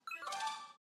Sound Effects